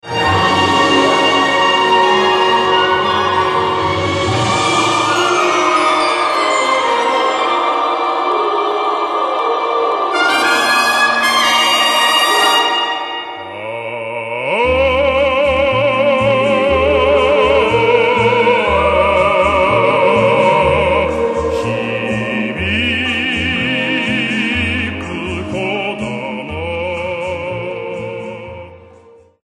It is the complete 1967 LP digitally remastered for CD.
stereo studio recording